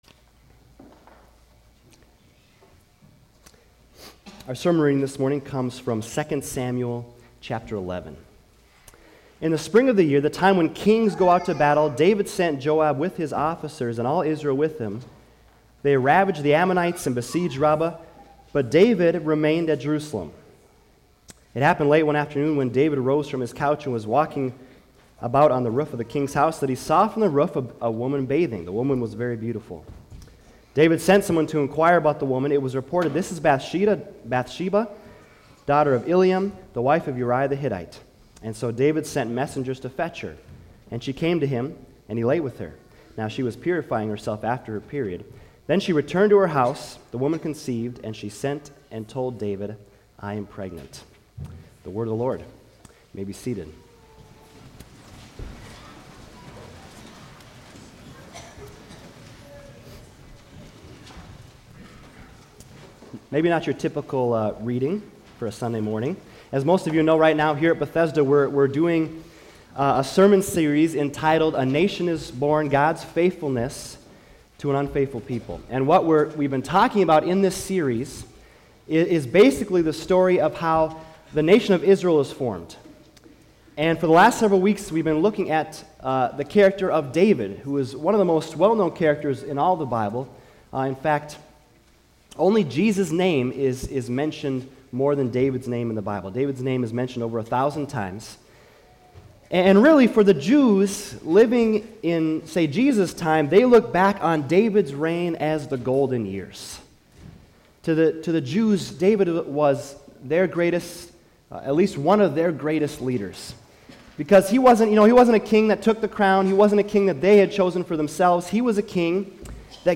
Sermons 2019 | Bethesda Lutheran Church